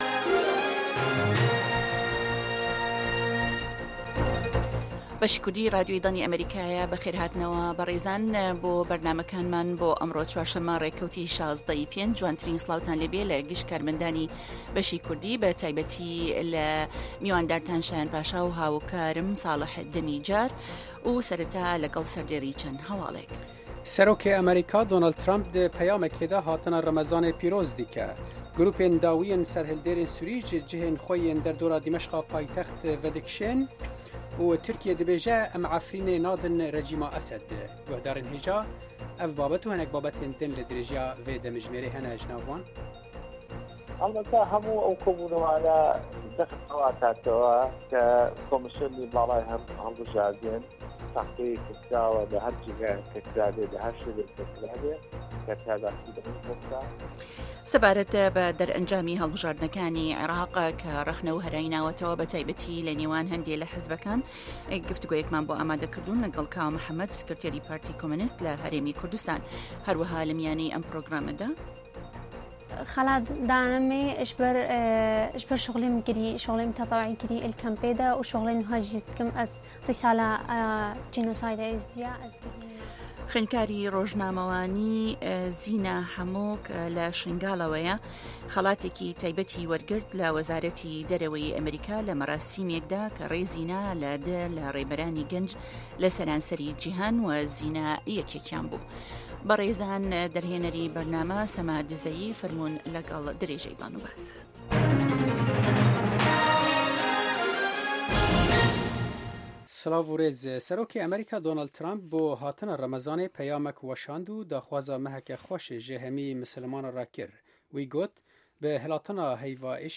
هه‌واڵه‌کان، ڕاپـۆرت، وتووێژ،